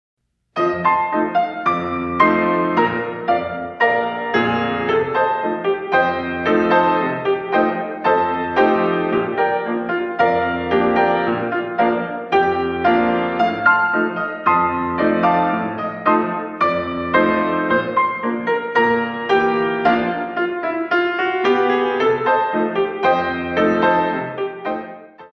In 2
64 Counts
Battement Tendu